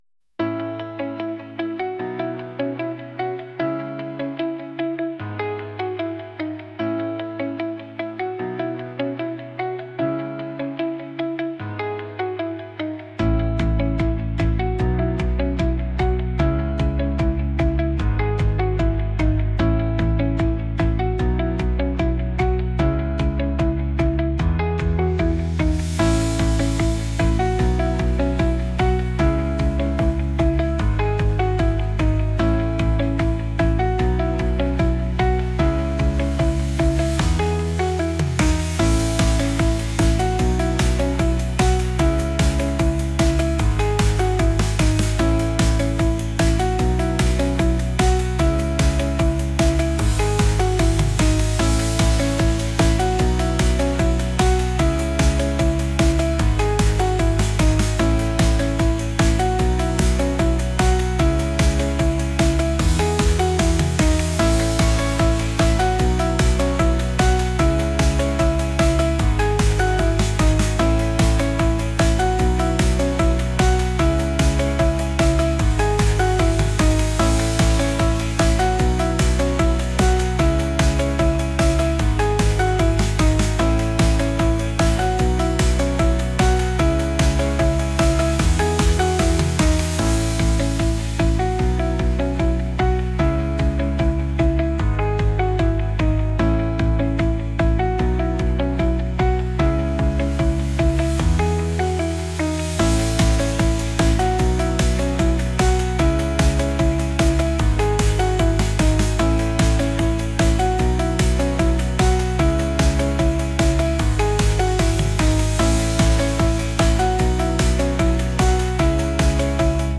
pop | retro | ambient